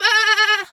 Animal_Impersonations
sheep_2_baa_high_05.wav